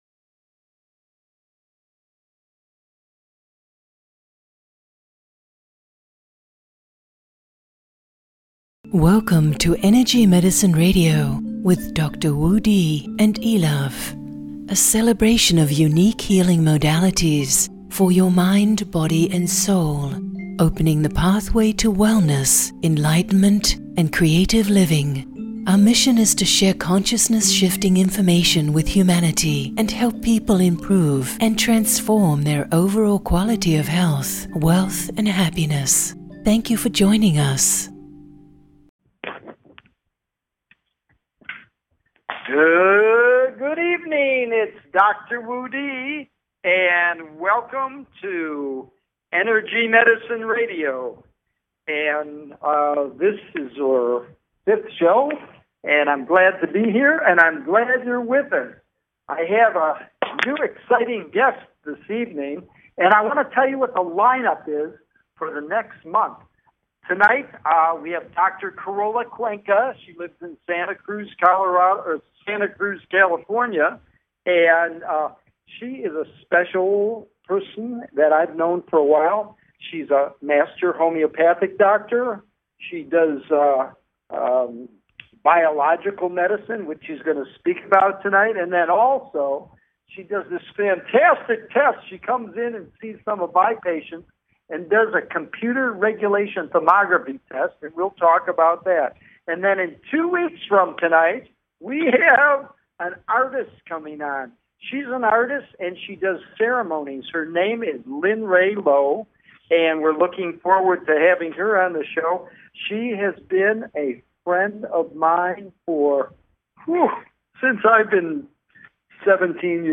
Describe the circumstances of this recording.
Live on Air: Every 1st and 3rd Tuesday 8-9pm EST (5-6pm PST) USA Join me as I support expansion into your full potential while paving the way to profound transformation and healing.